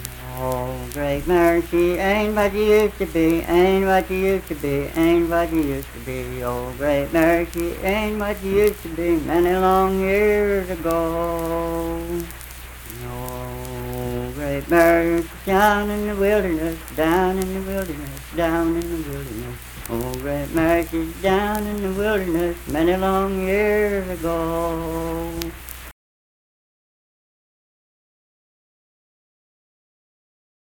Unaccompanied vocal music performance
Voice (sung)
Clay County (W. Va.)